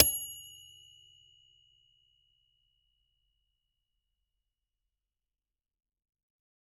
Ding (Air Fryer)
air-fryer bell ding microwave sound effect free sound royalty free Nature